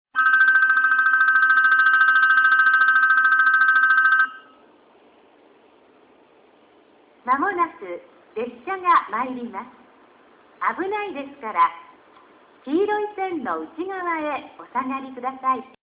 九州カンノ型・八代Ver.でした。
植木と同じタイプの放送です。